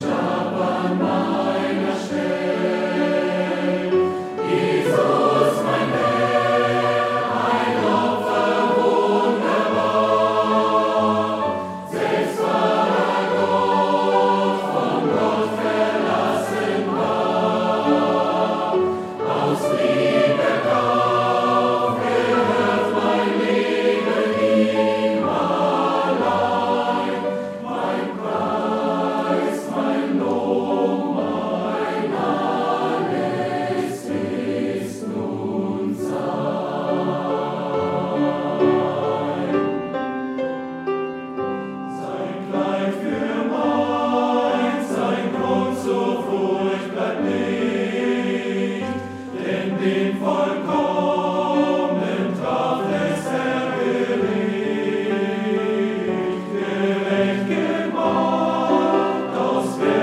• Sachgebiet: Chormusik/Evangeliumslieder